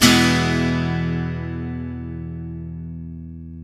GTR EL-AC 0A.wav